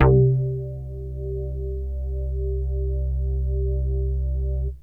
SYNTH BASS-2 0011.wav